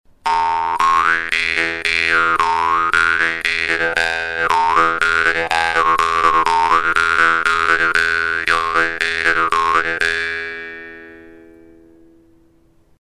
Die Wimmer-Bades Pro erinnert von der Form her an die sehr einfachen Modelle aus der gleichen Werkstatt, ihr Klang ist allerdings klar und die Stimmung sauber.
Ihre relativ straffe Zunge erzeugt einen lauten, scharfen Sound, der auch auf der Bühne mithalten kann.